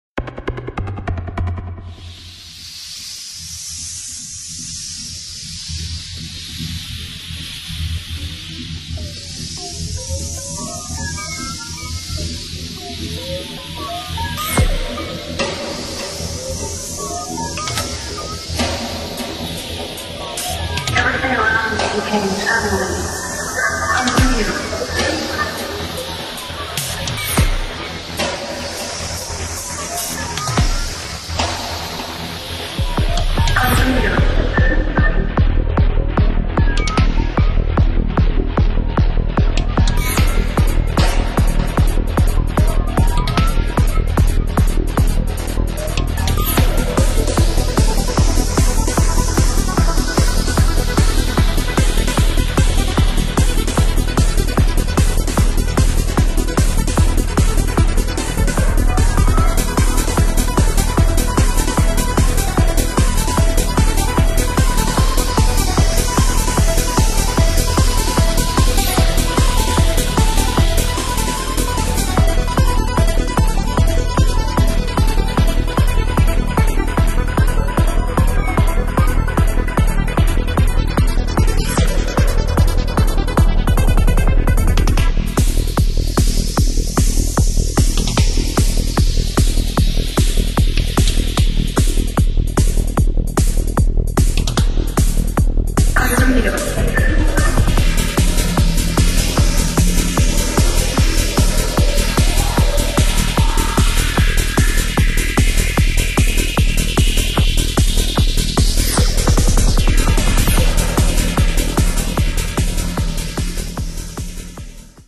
An attempt to Goa Trance!!